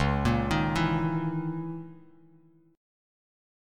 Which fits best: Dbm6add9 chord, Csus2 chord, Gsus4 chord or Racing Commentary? Dbm6add9 chord